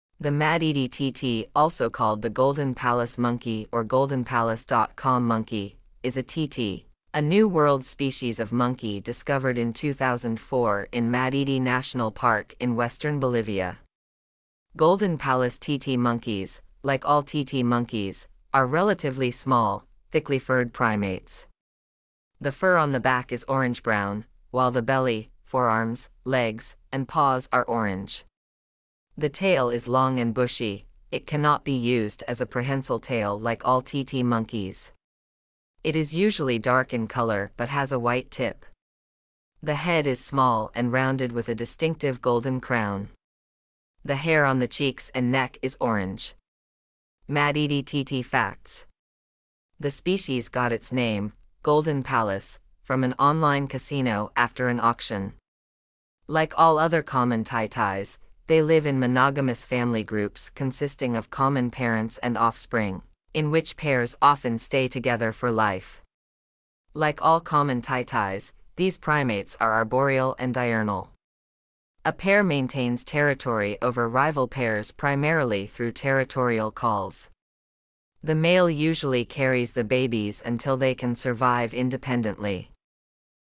Madidi-titi.mp3